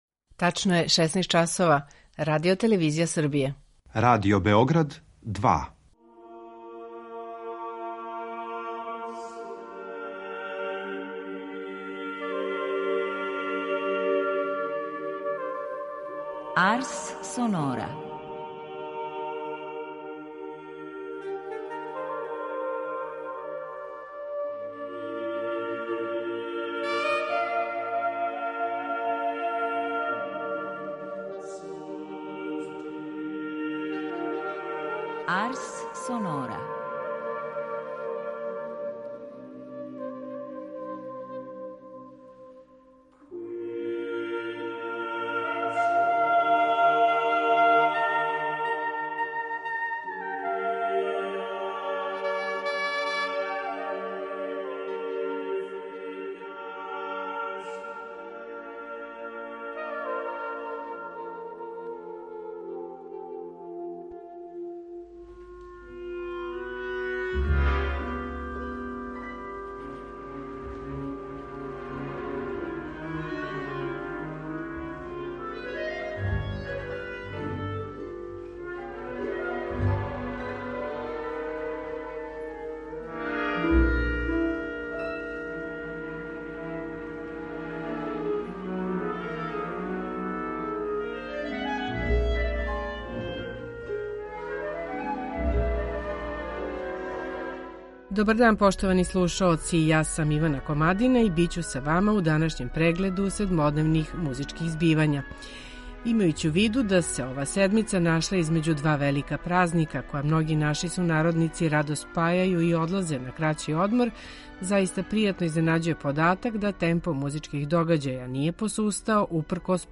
За данашњу Арс сонору Музичка редакција Радио Београда 2 издвојила је три концерта и једну промоцију монографије.